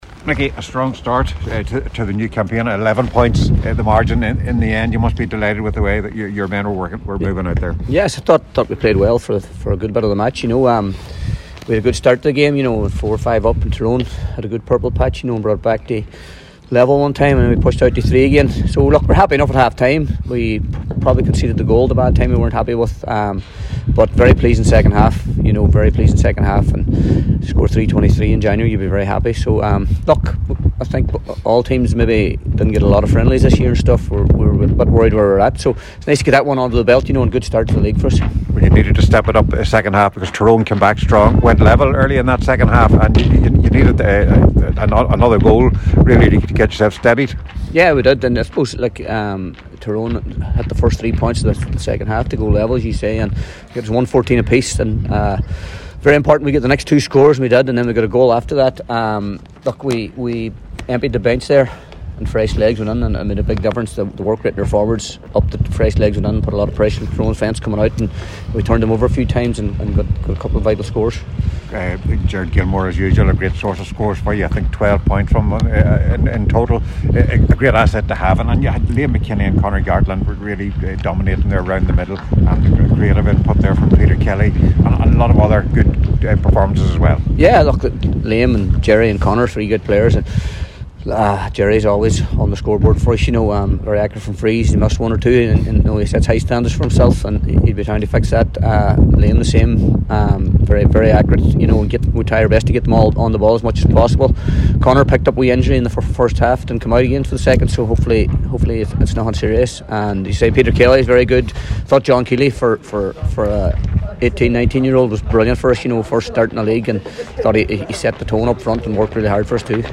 at full time this afternoon